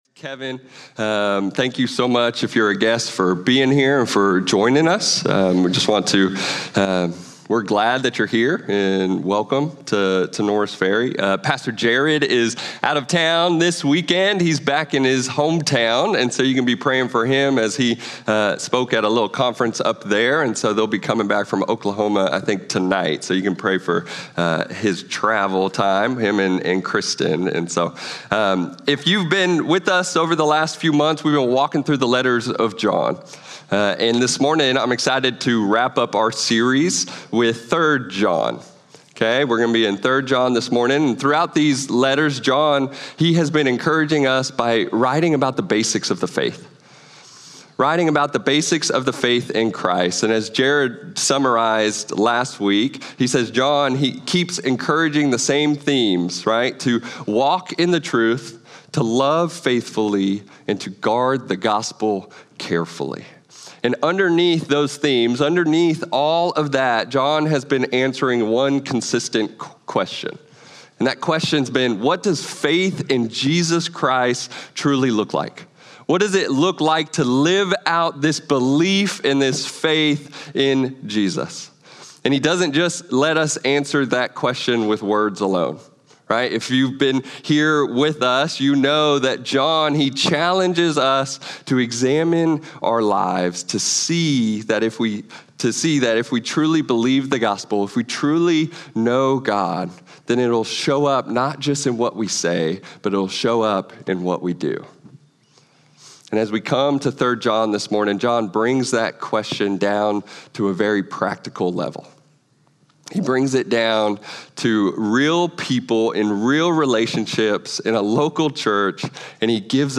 Norris Ferry Sermons Jan. 11, 2026 -- 3 John 1:1-15 Jan 11 2026 | 00:35:51 1x 00:00 / 00:35:51 Subscribe Share Spotify RSS Feed Share Link Embed